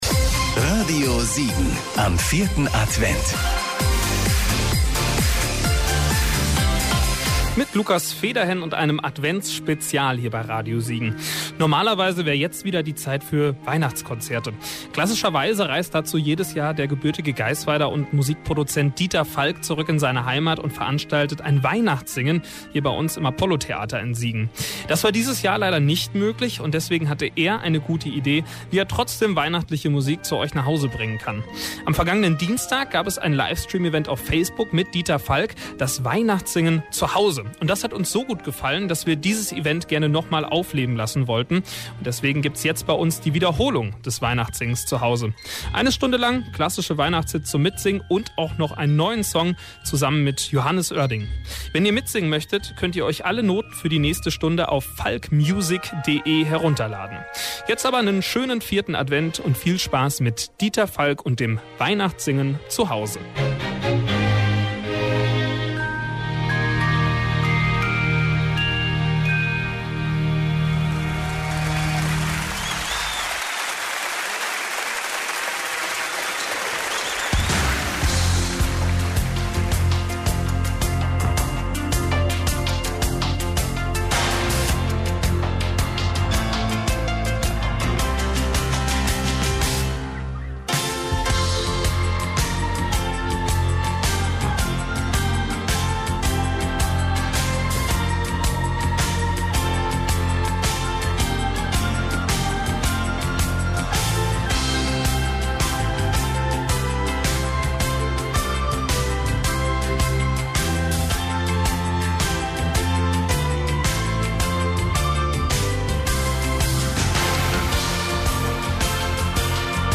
Dieter Falk: Erfolgsproduzent, Chorleiter, Musiker, Musikdozent und Popstars-Juror.
Seine schon traditionellen Weihnachtskonzerte im Siegener Apollo-Theater mussten dieses Jahr abgesagt werden, auch als Ersatz dafür gab es am 15. Dezember ein „WeihnachtsSingenZuhause“ – aus seiner Wahlheimat Düsseldorf live und online auf verschiedenen Sozialen Netzwerken.